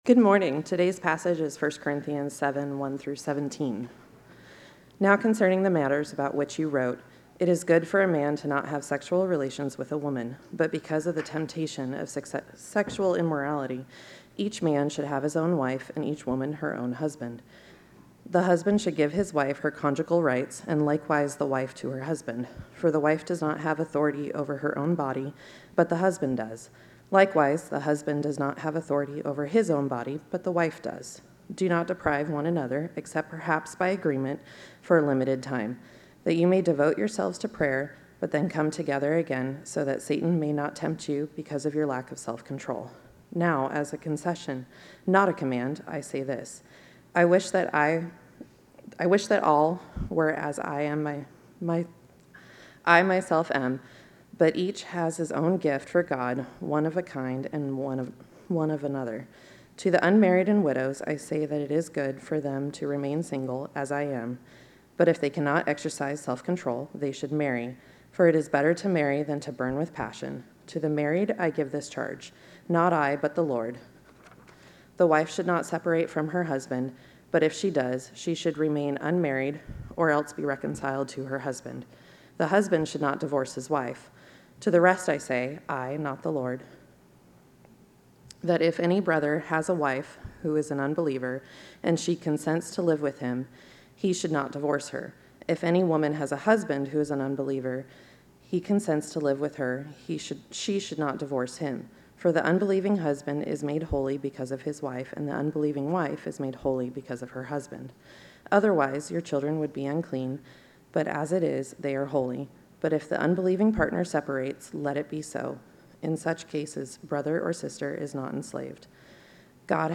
Sermon Notes: Love, Sex, and Singleness